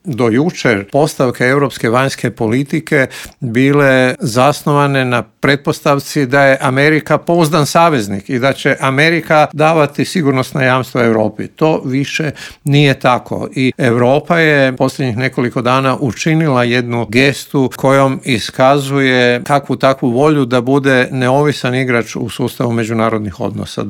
Na ta i ostala povezana pitanja u intervjuu Media servisa odgovorio je vanjskopolitički analitičar i bivši ambasador Hrvatske u Moskvi Božo Kovačević.